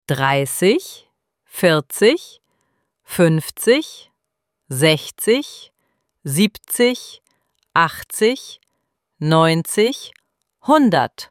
IZGOVOR – BROJEVI 30-100:
ElevenLabs_Text_to_Speech_audio-48.mp3